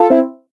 drone.ogg